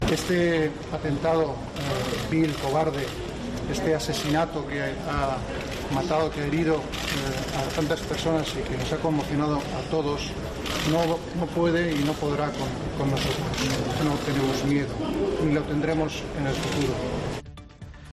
Así lo ha señalado don Felipe al finalizar la visita, junto a doña Letizia, a los heridos en el atentado perpetrado el jueves pasado en Barcelona y que permanecen ingresados en el Hospital del Mar y de Sant Pau.
Visiblemente emocionado, el Rey ha indicado que querían "estar cerca de las víctimas" para trasladarles su cariño y su afecto en estos momentos, y también para agradecer a los profesionales sanitarios su labor tras los atentados.